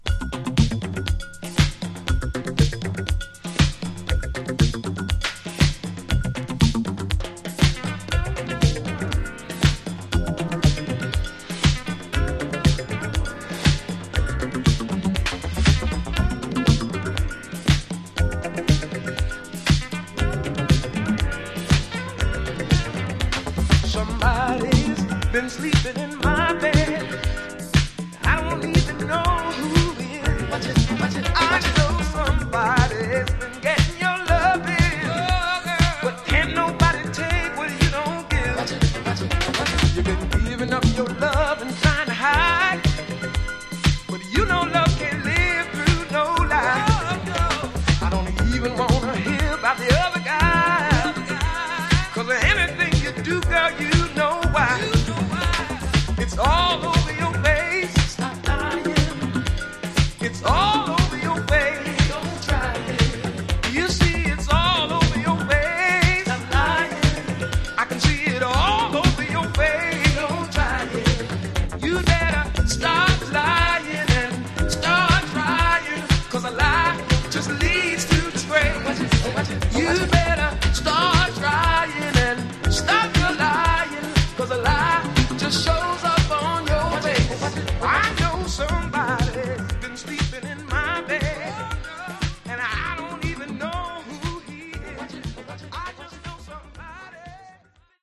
This amazing rhythm concoction